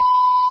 S.P.L.U.R.T-Station-13/sound/vox_fem/scensor.ogg
* New & Fixed AI VOX Sound Files